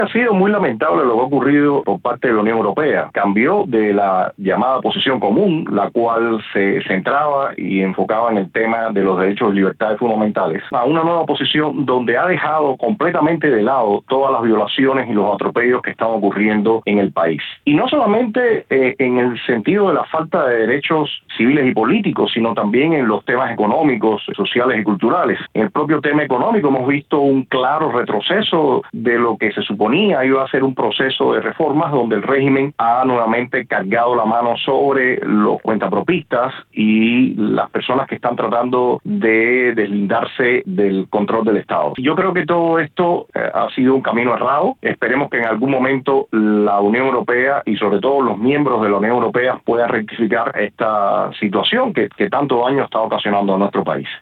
Declaraciones